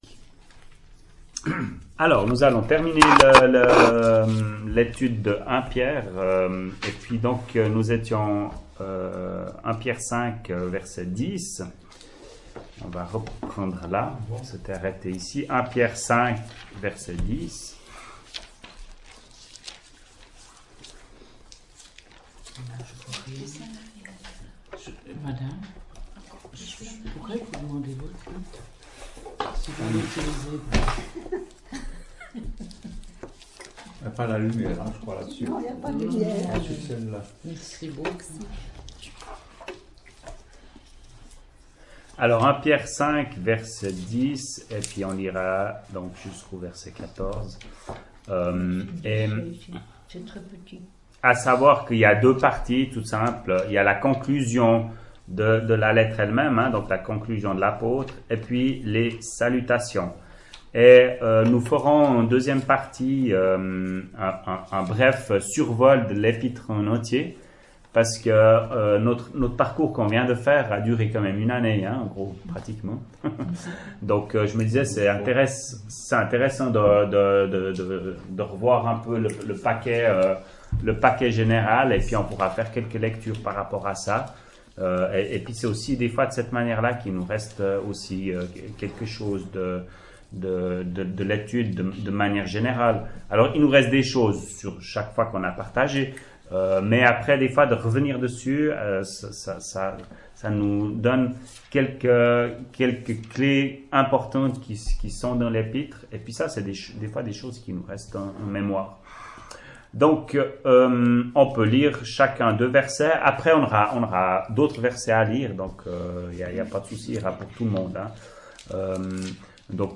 [Chapelle de l’Espoir] - Étude biblique : Première Épître de Pierre, 12ème partie
ÉTUDE BIBLIQUE : Evole, le 29.08.2018